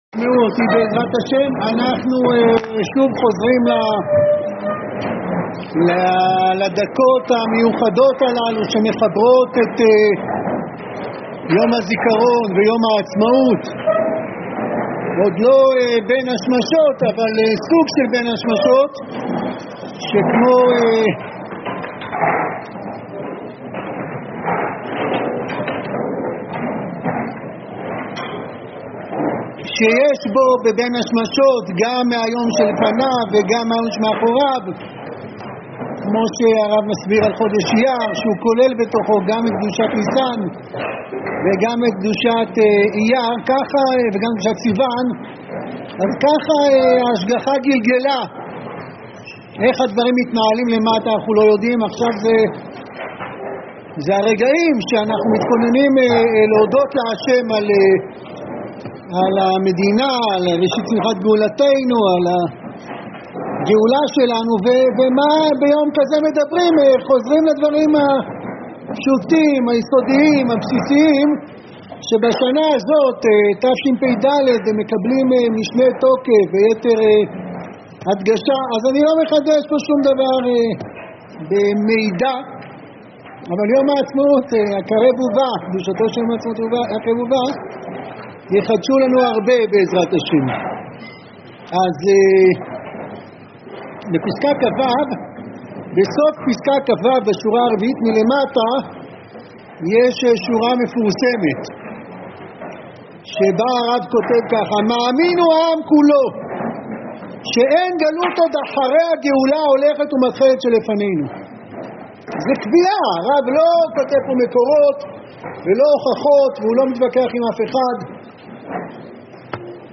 שיעור-כללי-ליום-העצמאות-1.mp3